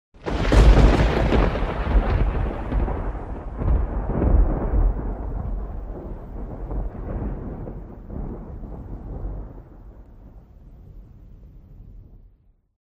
thunder